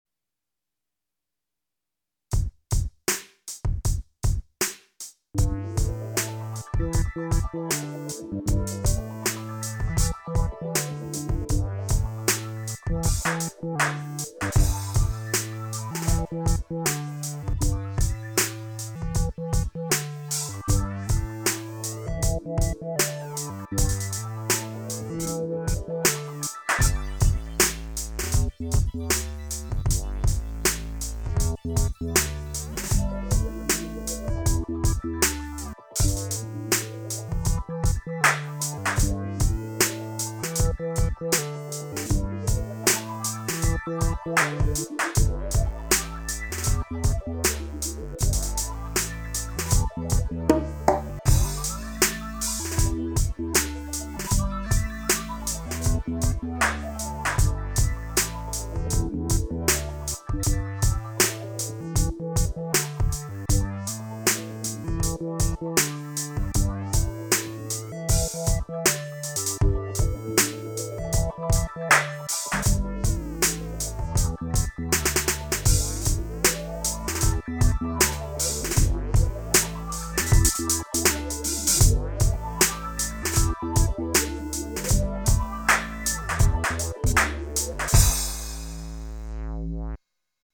Home > Music > Beats > Medium > Laid Back > Chasing